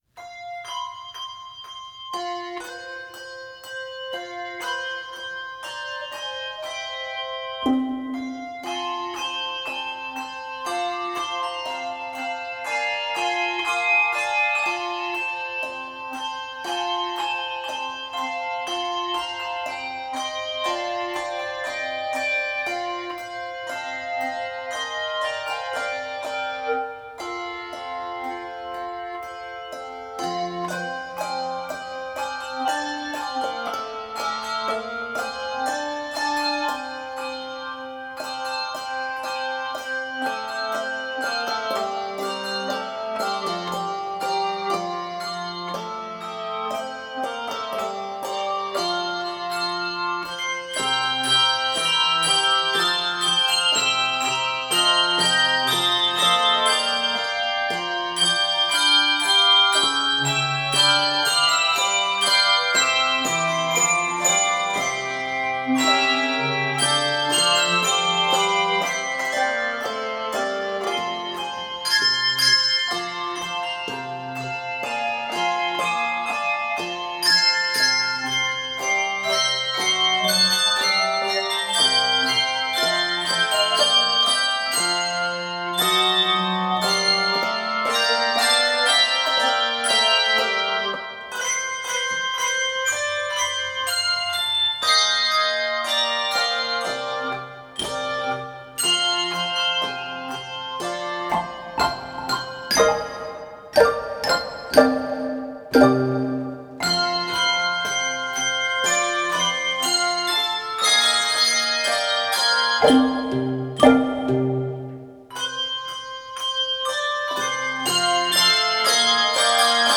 Christmas hymn
Scored for 3–5 octave handbells.
Key of F Major.